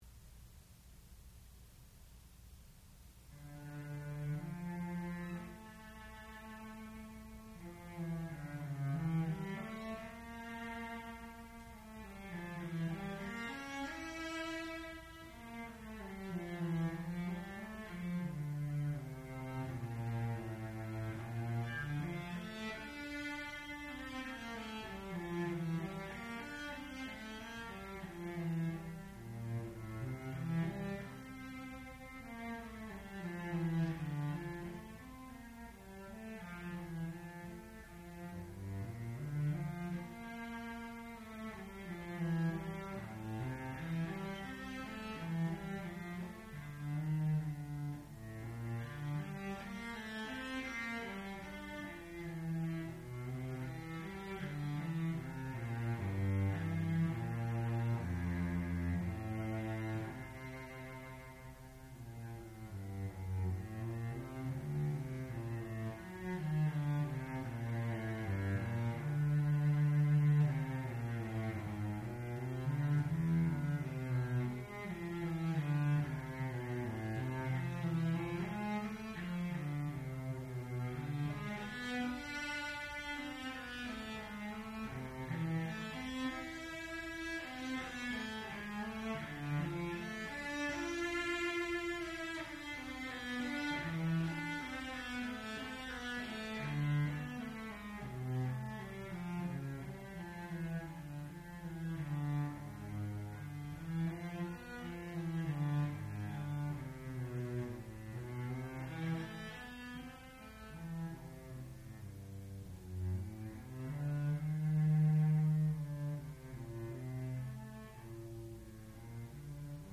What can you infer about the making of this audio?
Senior Recital